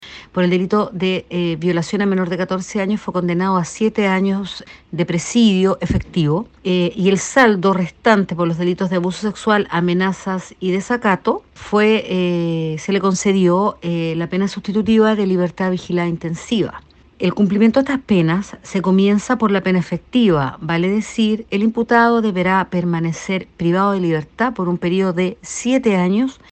La fiscal del Ministerio Público, Karyn Alegría, explicó detalles del delito y de qué forma el condenado cumplirá el total del castigo.